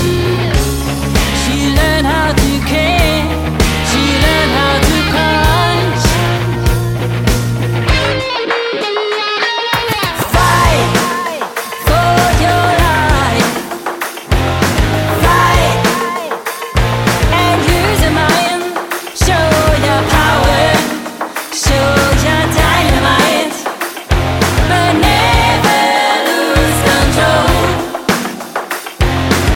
Gesang
Gitarre
Bass
Schlagzeug) ihre Leidenschaft für Rock- und Popmusik.